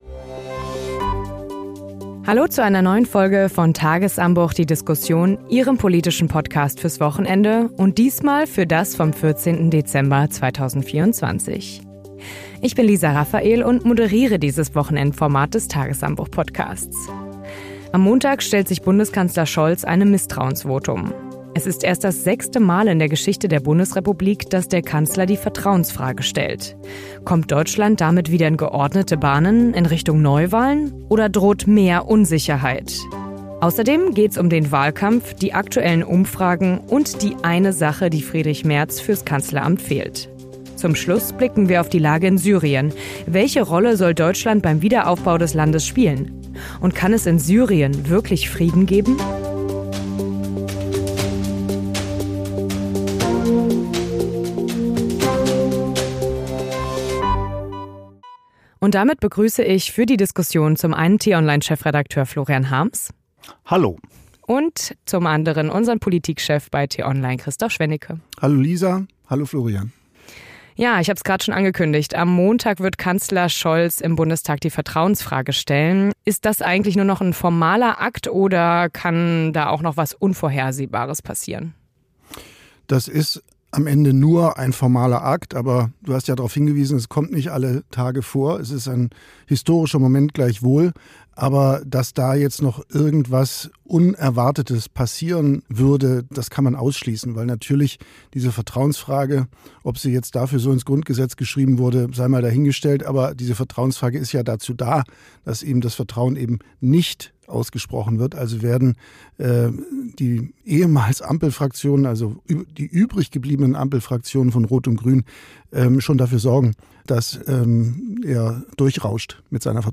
Konträre Meinungen treffen aufeinander und es geht um die Fragen: Welche Rolle soll Deutschland beim Wiederaufbau des Landes spielen?